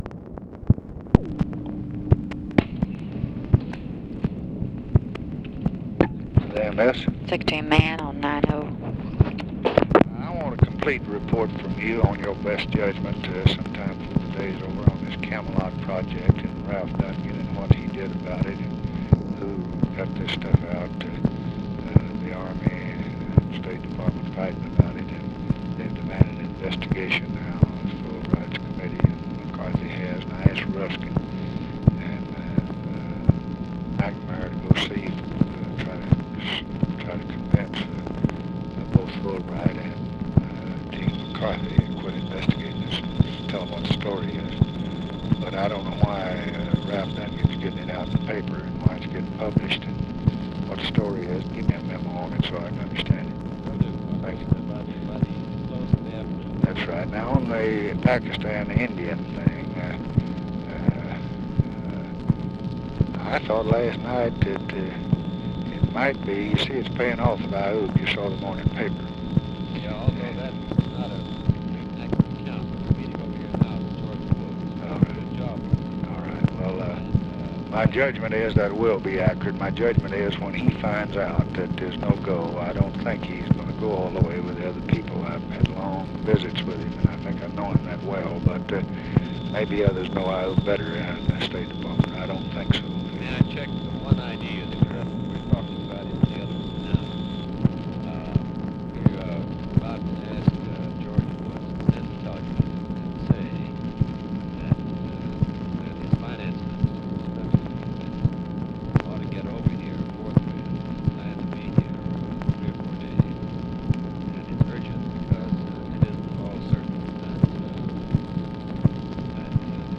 Conversation with THOMAS MANN, June 30, 1965
Secret White House Tapes